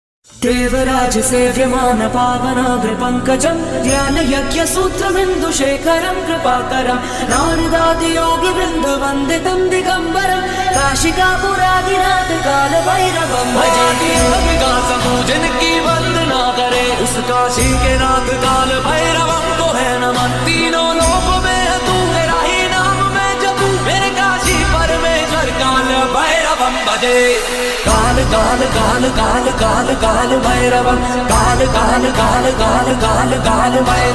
devotional ringtone